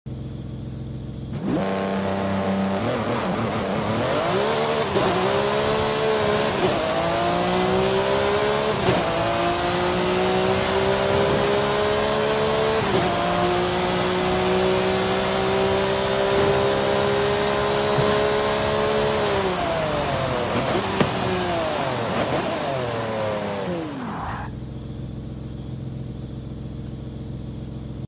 2005 Ferrari FXX Launch Control sound effects free download
2005 Ferrari FXX Launch Control & Sound - Forza Horizon 5